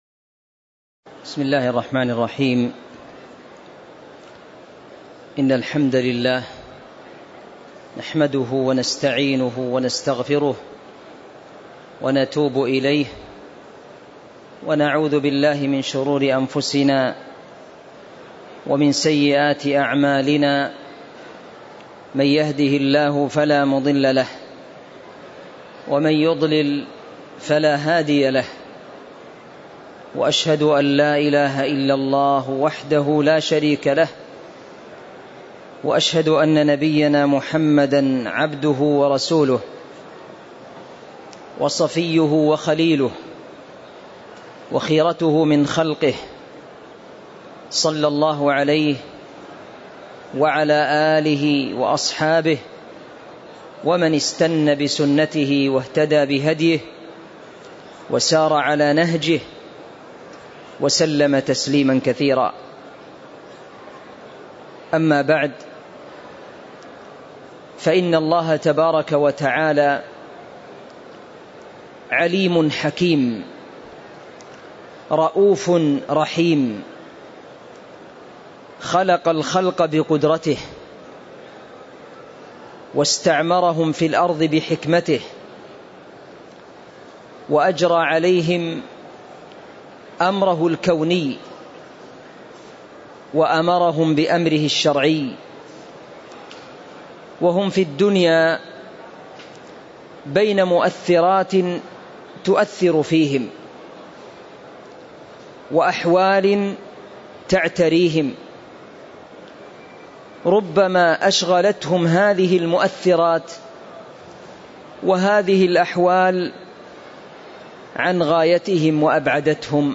تاريخ النشر ٢٥ شعبان ١٤٤٥ هـ المكان: المسجد النبوي الشيخ